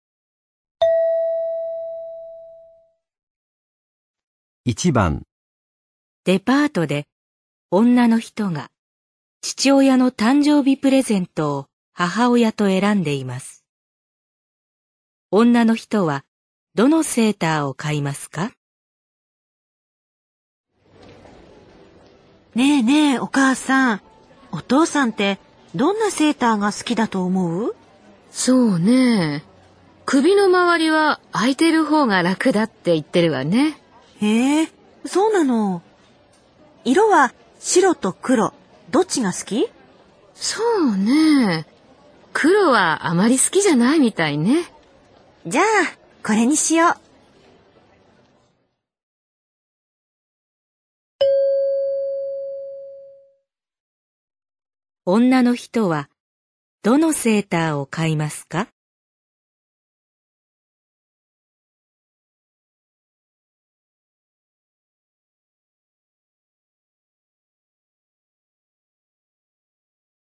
在百货商店里，女孩正和母亲一起挑选父亲的生日礼物。